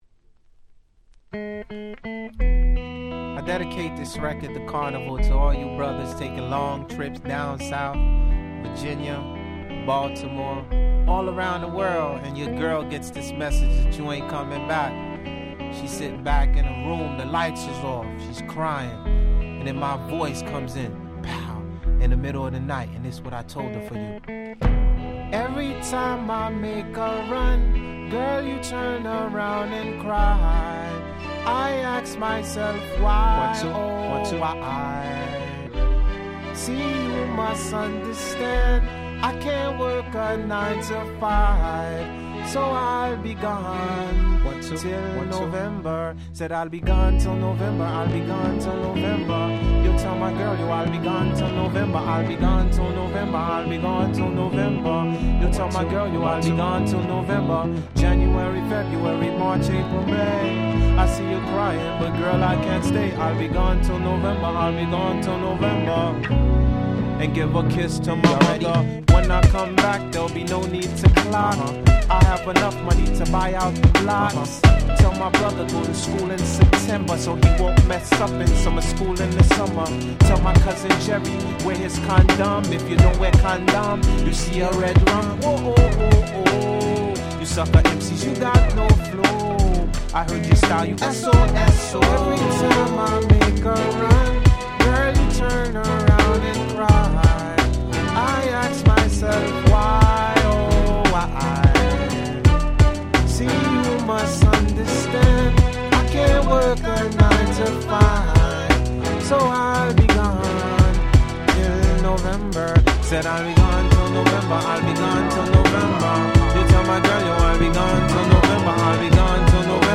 97' Super Hit R&B !!